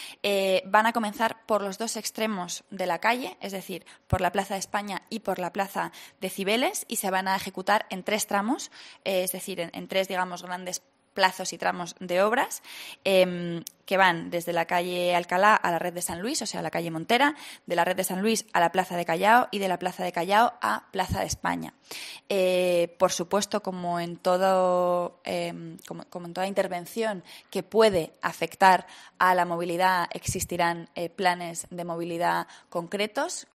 Rita Maestre, portavoz del Gobierno municipal